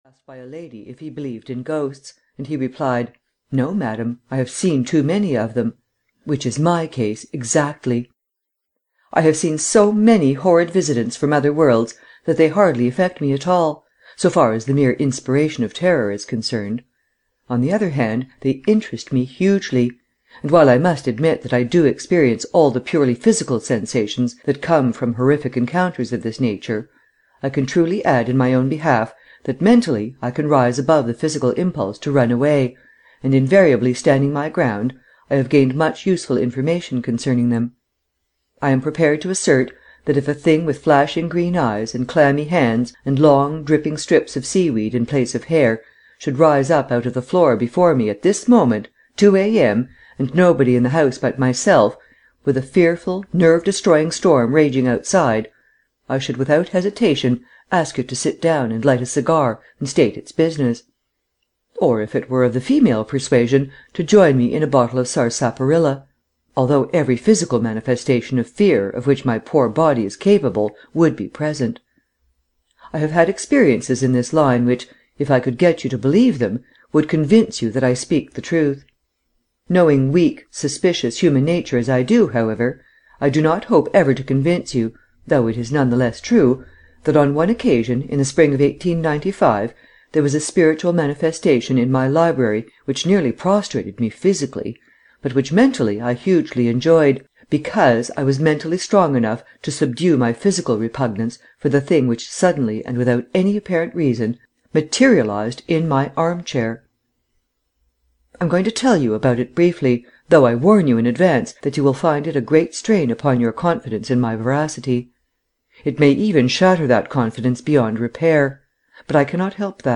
Ghosts I have Met and Some Others (EN) audiokniha
Ukázka z knihy